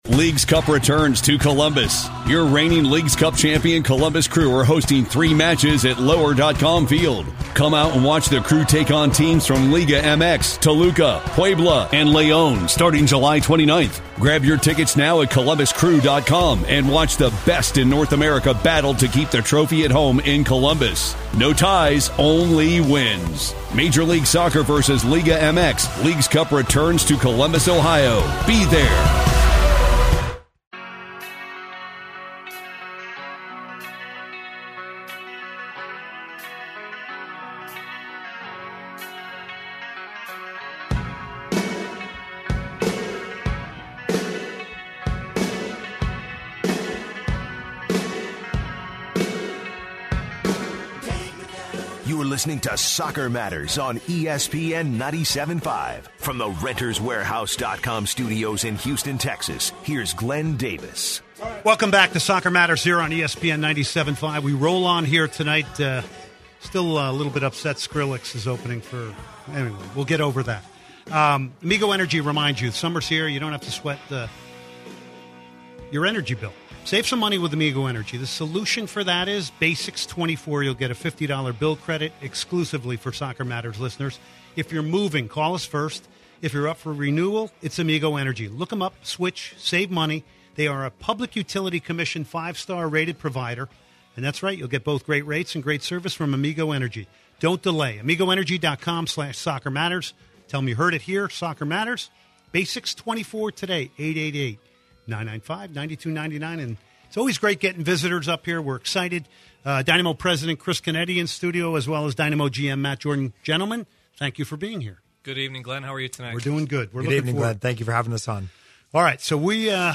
Listeners call in wanting answers about the direction the club is heading in. listen to tonight’s podcast to find out about this and much more.